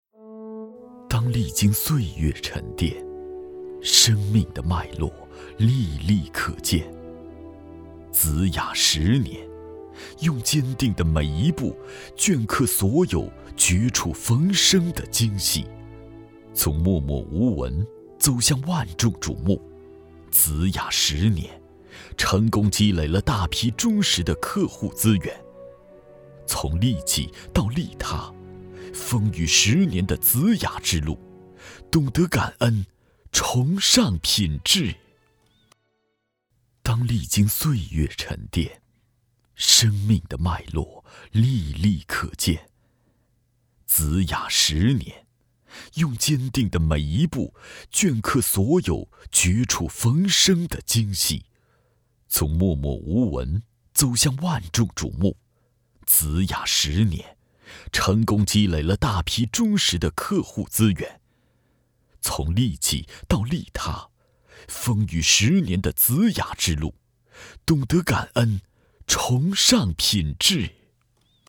男64号